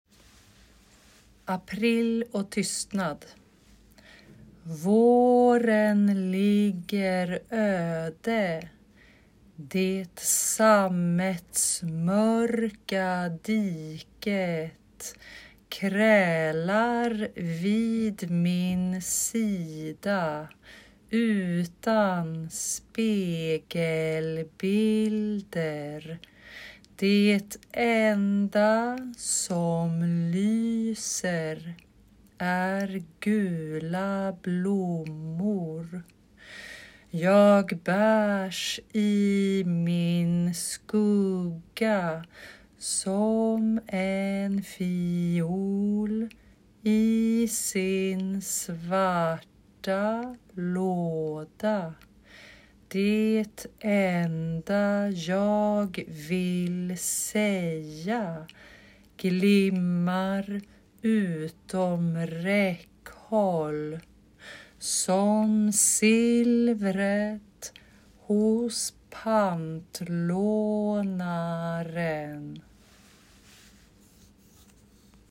APRIL OCH TYSTNAD Swedish pronunciation guides: